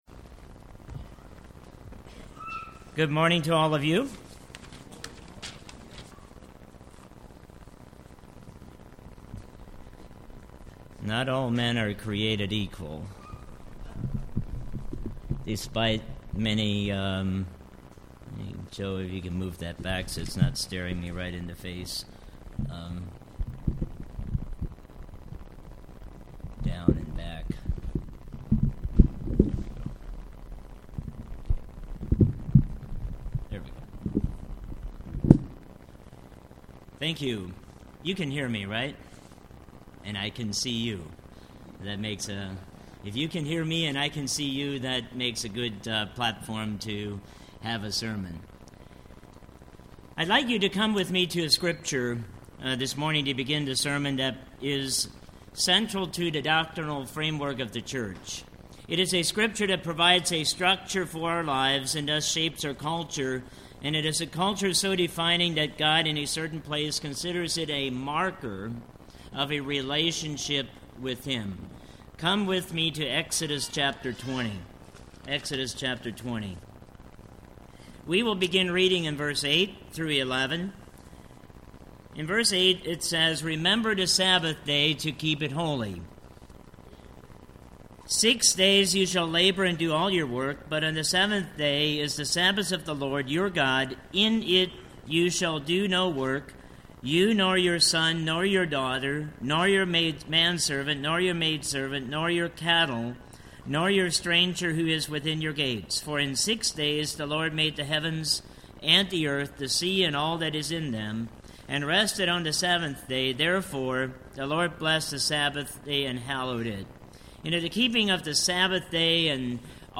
The Sabbath commandment tells us to rest on the seventh day, but it also tells us to work for 6 days, this sermon examines the Destiny of the Diligent.